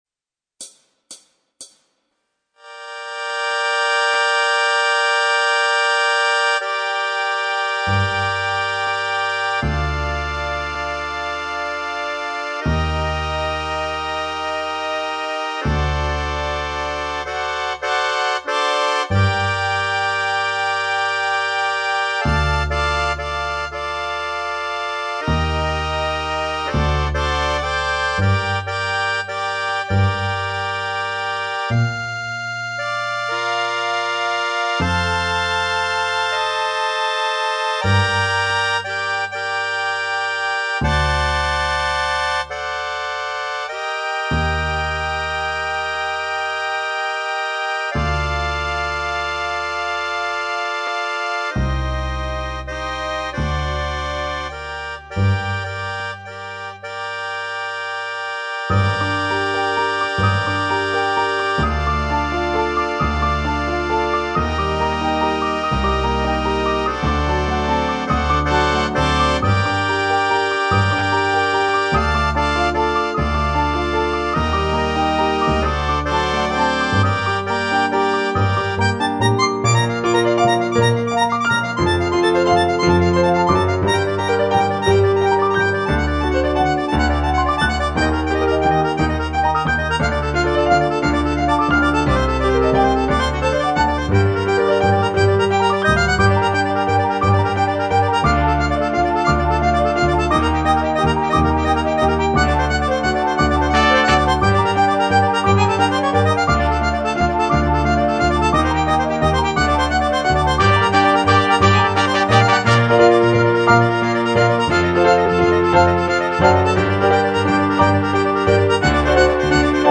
C'est en Sol mineur.